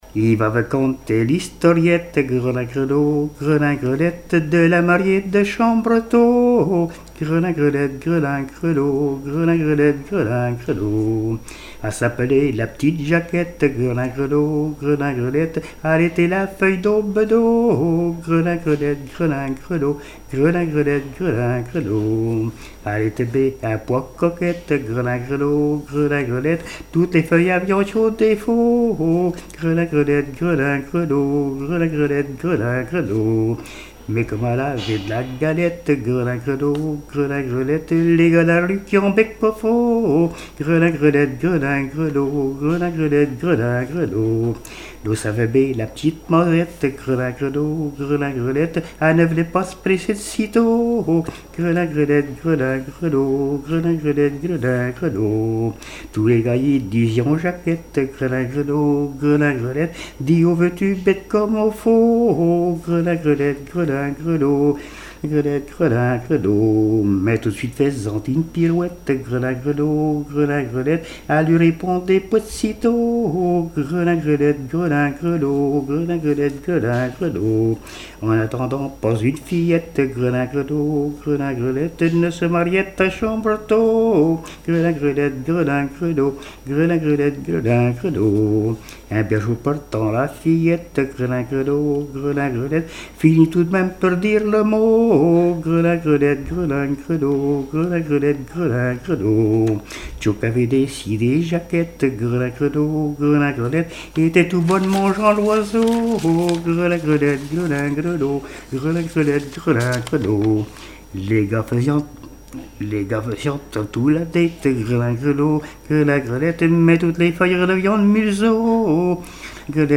chansons et témoignages
Pièce musicale inédite